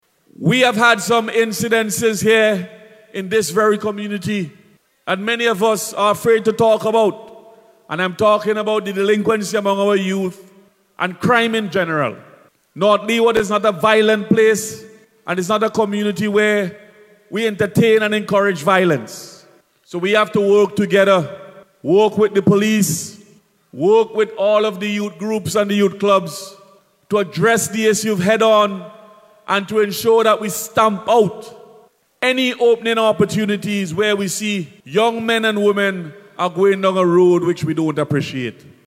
Parliamentary Representative for the North Leeward Constituency and Minister of Culture, Carlos James made this appeal while delivering an address as part of a Pan against Crime Initiative at the Petit Bordel Secondary School, over the weekend.